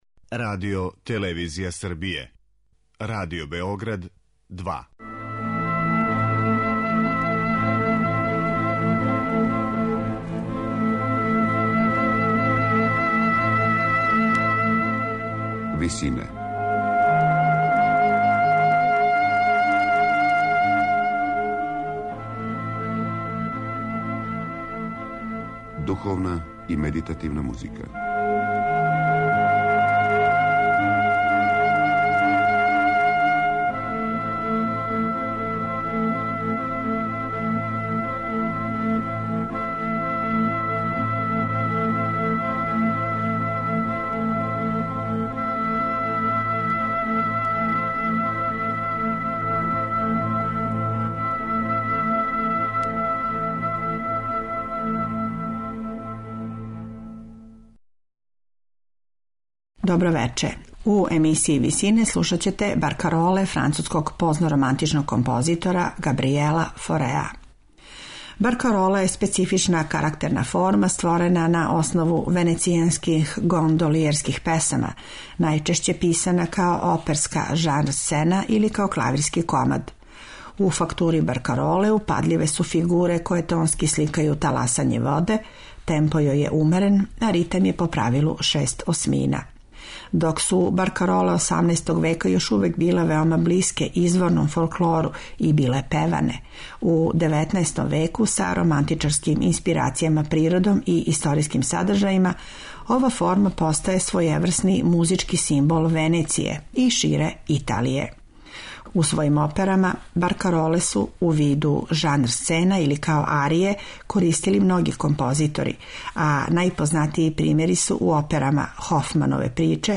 На крају програма, у ВИСИНАМА представљамо медитативне и духовне композиције аутора свих конфесија и епоха.
Емитоваћемо баркароле француског позног романтичара Габријела Фореа, у интерпретацији пијанисте Жана-Филипа Којара.